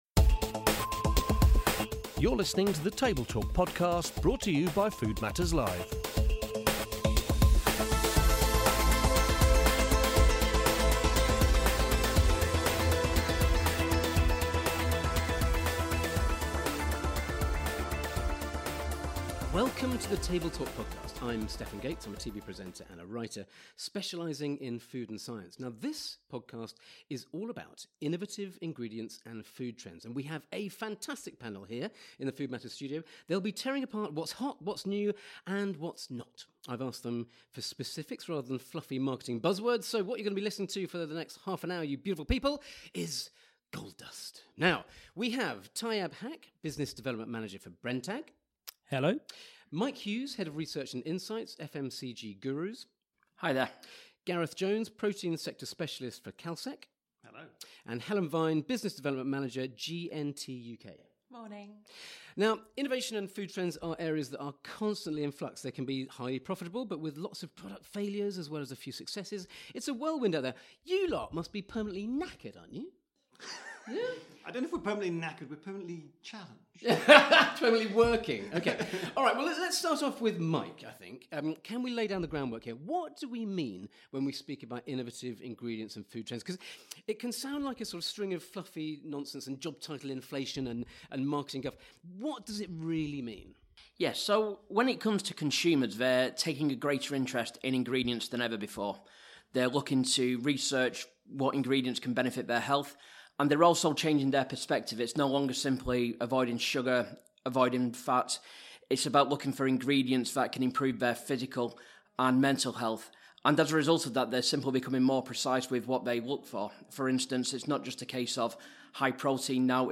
In the latest podcast we sat down with four experts from Brenntag, Kalsec, GNT and FMCG Gurus who will explain how ingredients companies manage to respond to these changing trends, and what they see as the trends that will drive the future of food and drink.